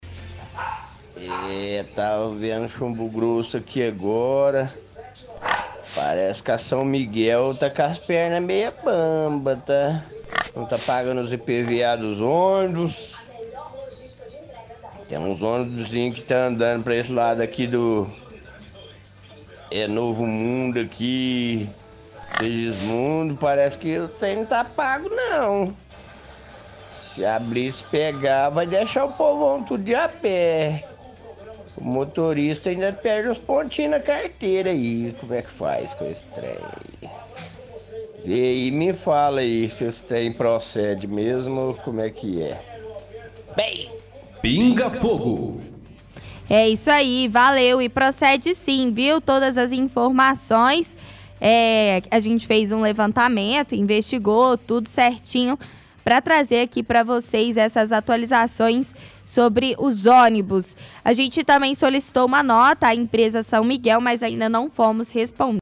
– Ouvinte comenta sobre matéria do Chumbo grosso em que mostra que a empresa São Miguel, que presta serviços de transporte público para Uberlândia, está com IPVA atrasado.